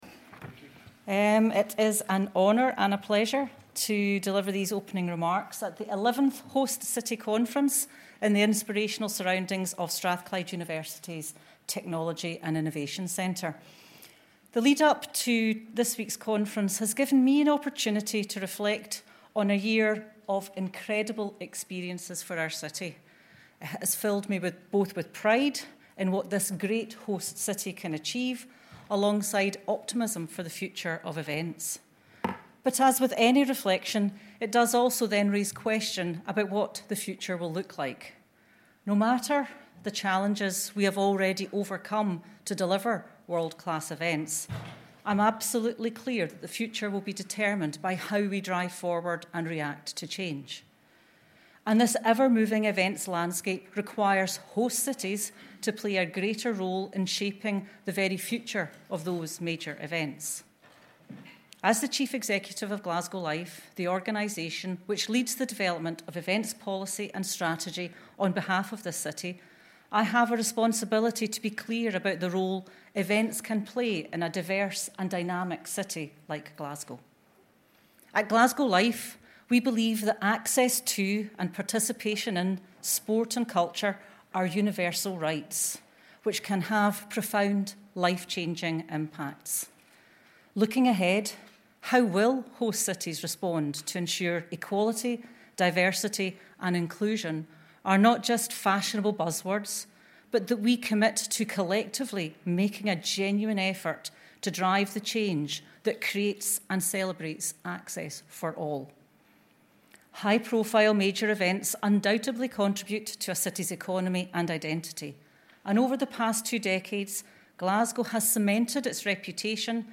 Glasgow Life Welcome Keynote Address